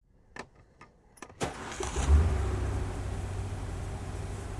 car / start.wav